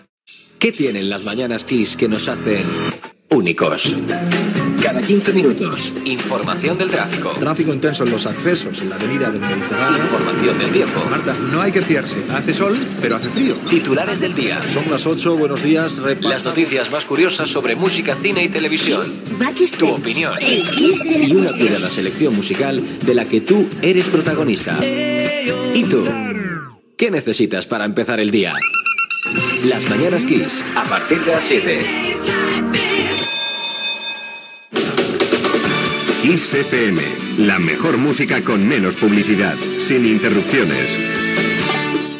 Promoció de "Las mañanas Kiss" i indicatiu de la ràdio